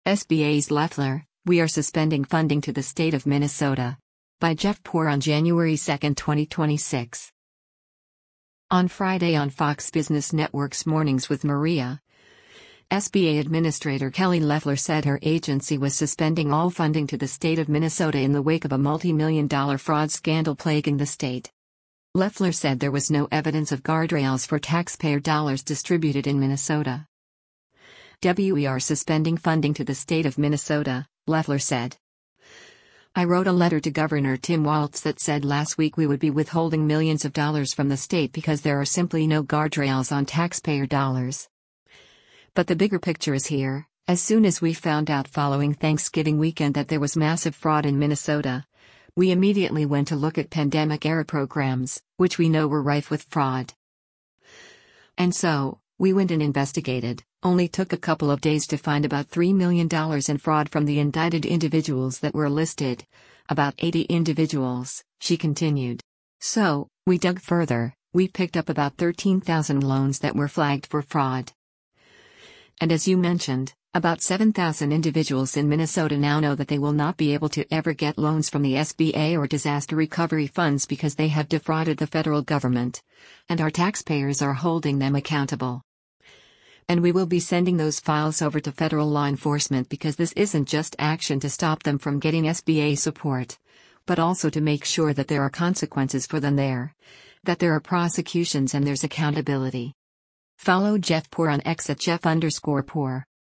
On Friday on Fox Business Network’s “Mornings with Maria,” SBA Administrator Kelly Loeffler said her agency was suspending all funding to the state of Minnesota in the wake of a multimillion-dollar fraud scandal plaguing the state.